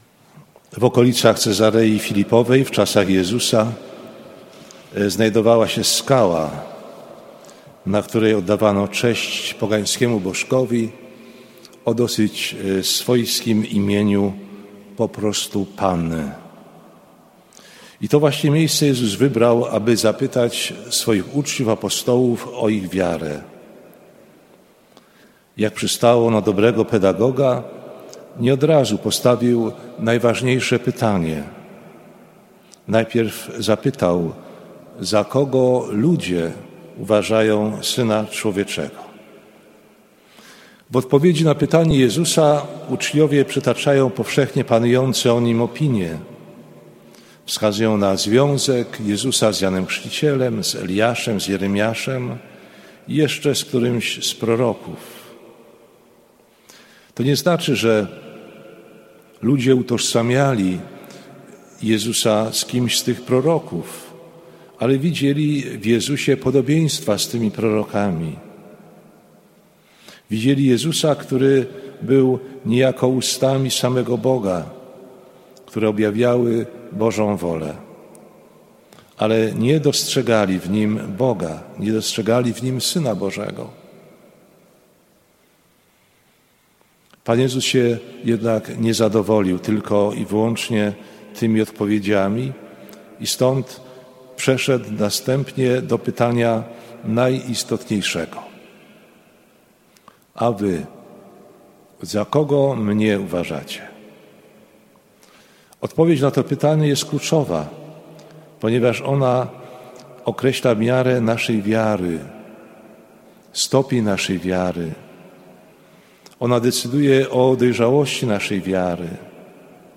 kazanie-biskupa-Wieslawa-Lechowicza-do-absolwentow-WAT.mp3